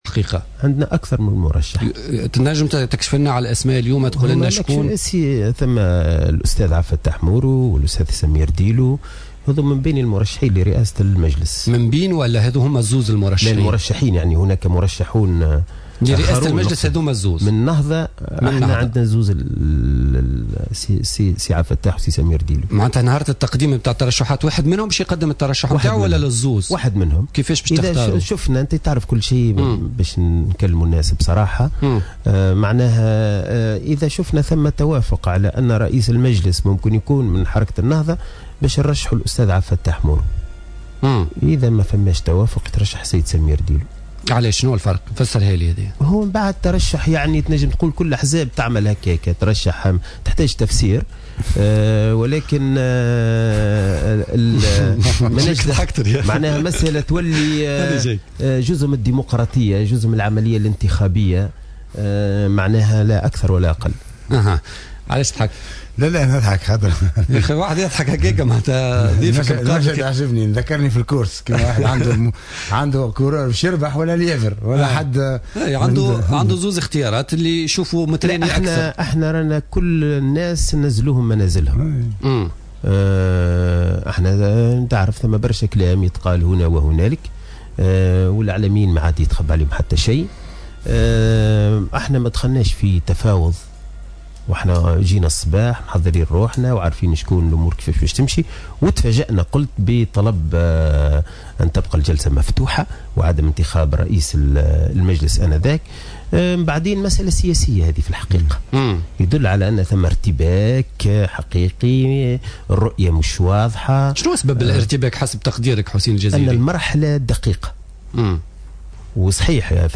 أكد القيادي في حركة النهضة حسين الجزيري، ضيف حصة الاربعاء من برنامج بوليتيكا على جوهرة أف أم، أن حركة النهضة سترشح عبد الفتاح مورو سمير ديلو لتولي رئاسة مجلس نواب الشعب وأن الحركة ستقرر ترشيح أحدهما حسب ما سيفرزه المشهد السياسي في الفترة القادمة.